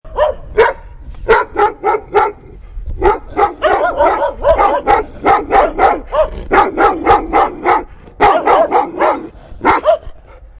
Angry Dogs Sound Button - Free Download & Play